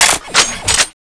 shootb_on2.wav